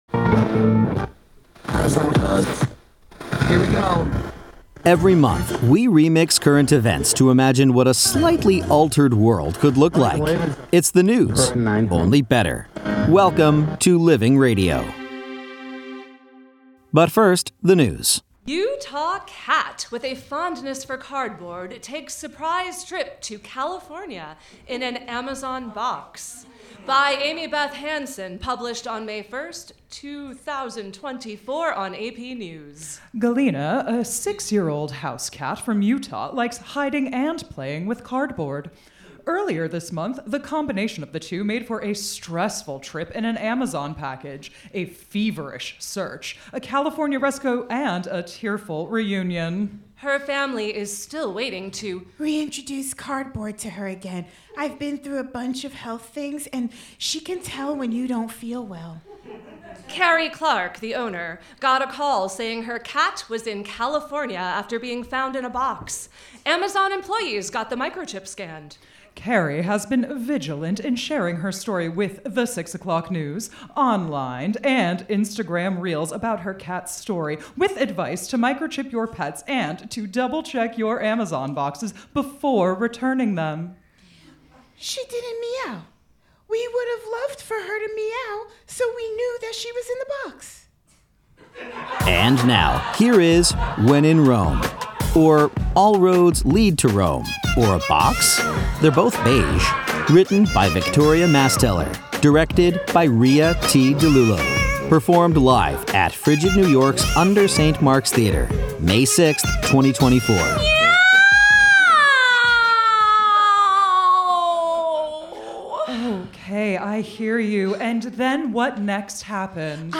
performed live at FRIGID New York’s UNDER St. Mark’s Theater, May 6, 2024